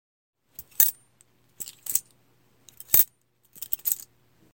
刀具
描述：有人打开和关闭蝴蝶刀的声音
Tag: 蝴蝶刀 金属 叮当声